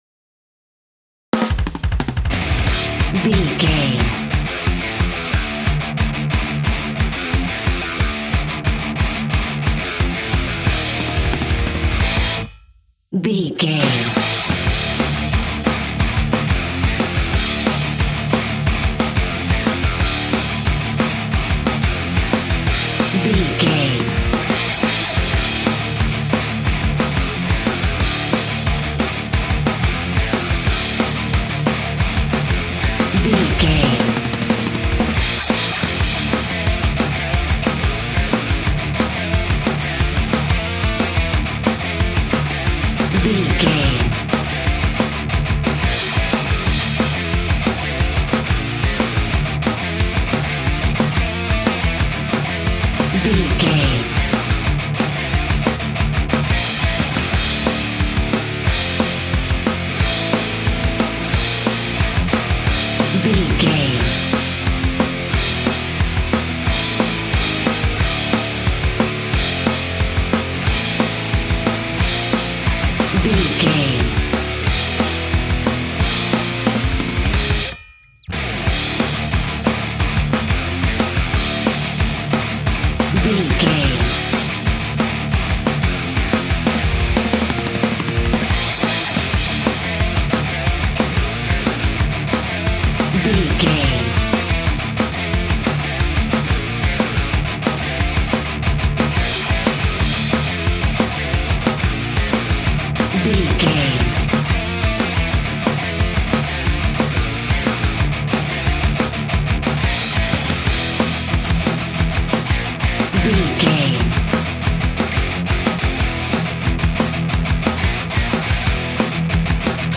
Epic / Action
Fast paced
Aeolian/Minor
hard rock
heavy metal
instrumentals
Rock Bass
heavy drums
distorted guitars
hammond organ